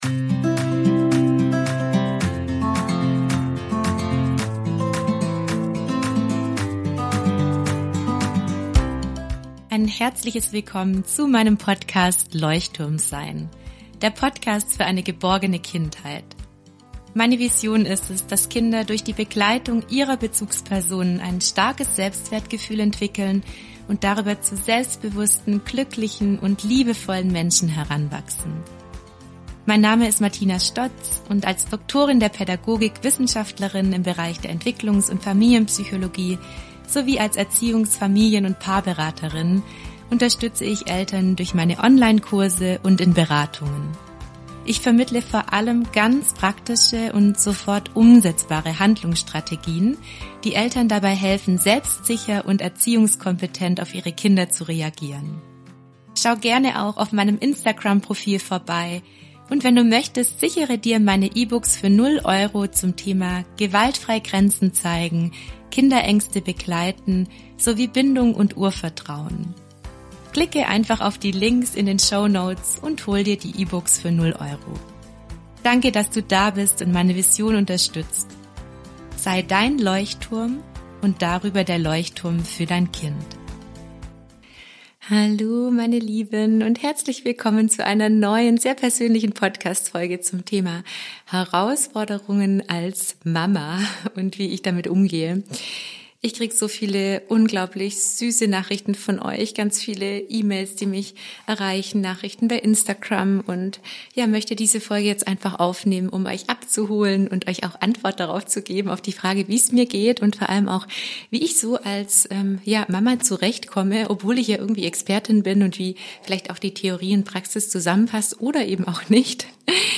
In dieser kurzen Solo-Folge berichte ich dir ganz ehrlich von meinen Herausforderungen als Mama und wie ich versuche, mit diesen umzugehen. Ich spreche darüber, wie ich als Expertin an meine Grenzen komme, und wie ich meine Selbstregulation und Bindungsfähigkeit stärke.